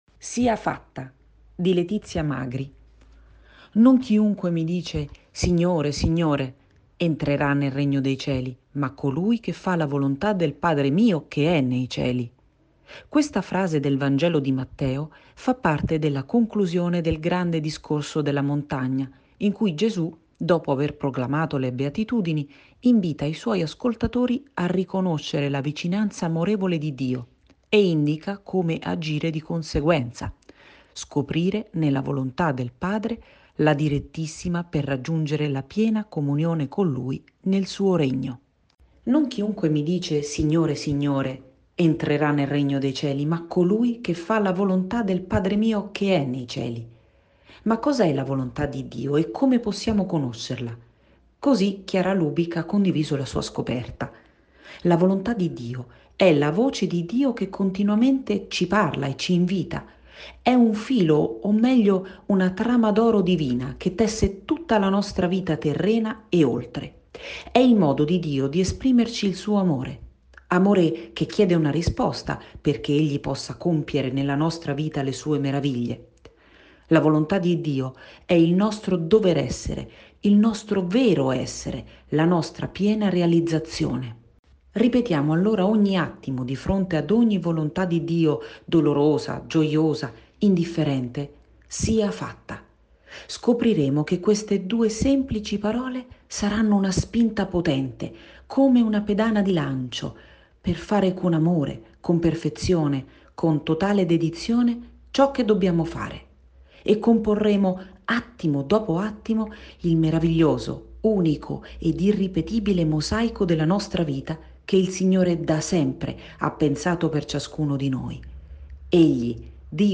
In profondità > Audioletture